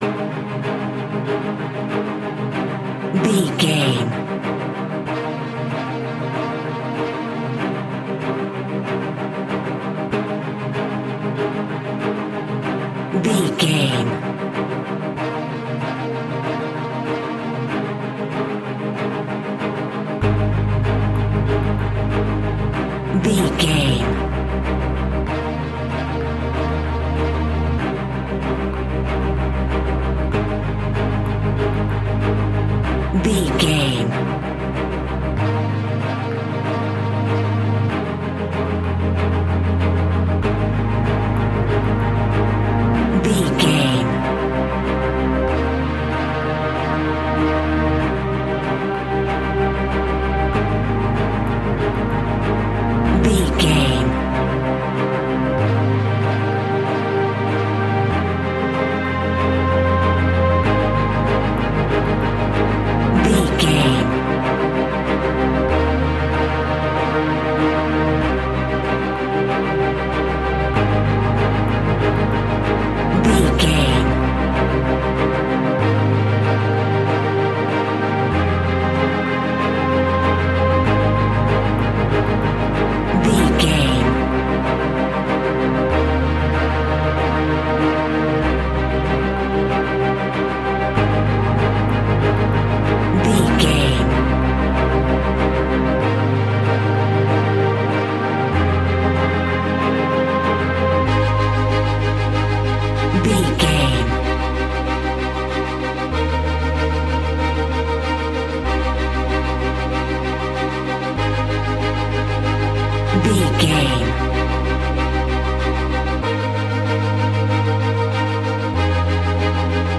Epic / Action
Fast paced
In-crescendo
Aeolian/Minor
strings
brass
percussion
synthesiser